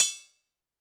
Drums_K4(24).wav